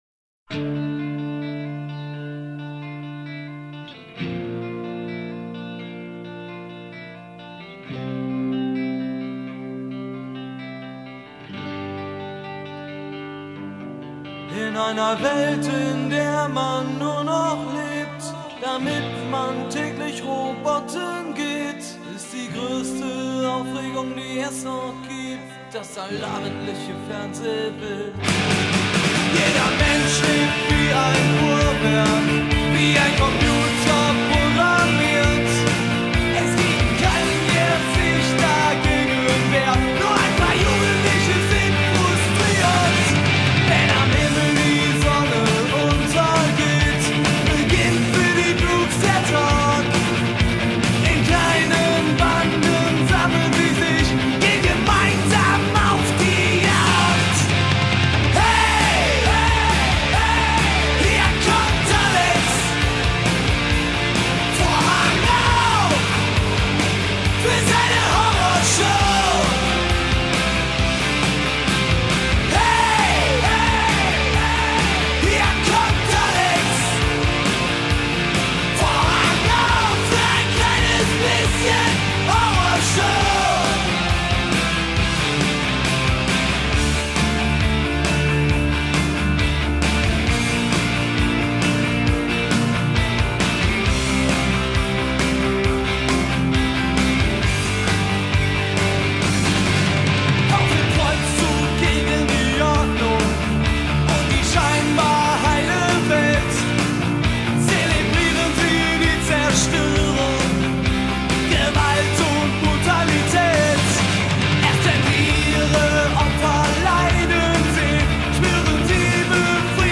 پانک راک